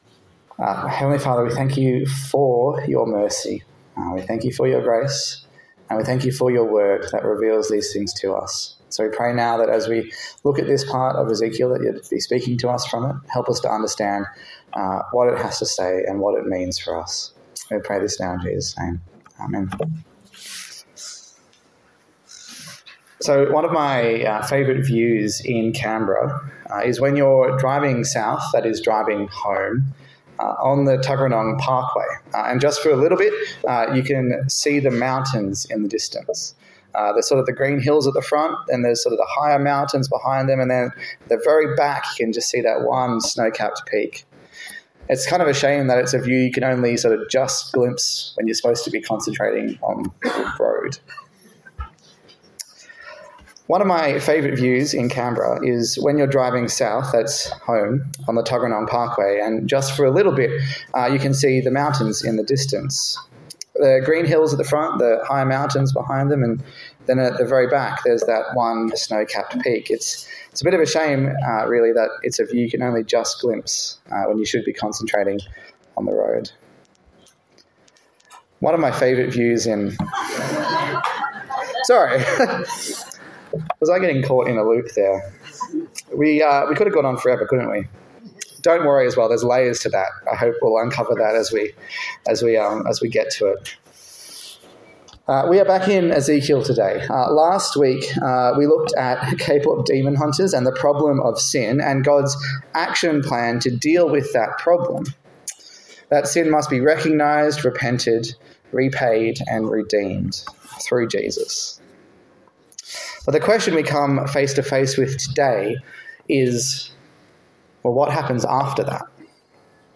A sermon in the series on the book of Ezekiel
Service Type: Morning Service